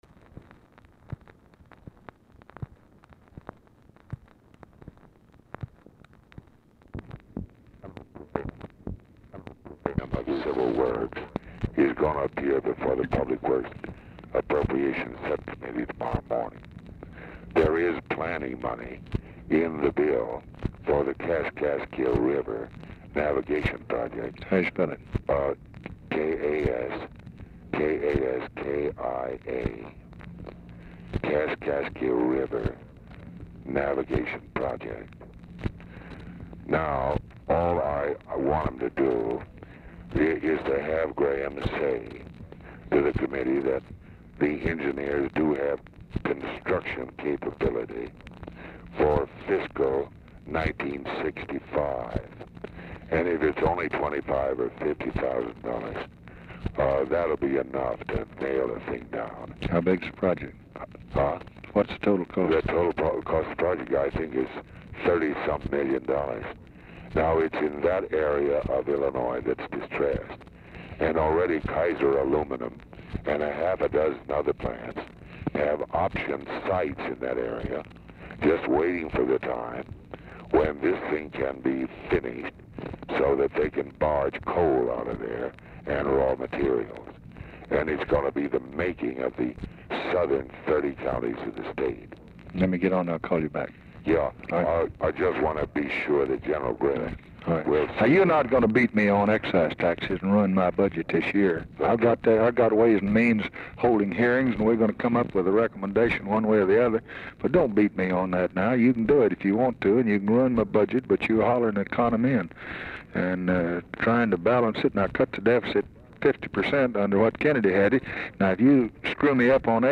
Telephone conversation # 3856, sound recording, LBJ and EVERETT DIRKSEN, 6/23/1964, 6:00PM | Discover LBJ
Format Dictation belt
Location Of Speaker 1 Oval Office or unknown location